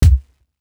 Wu-RZA-Kick 21.wav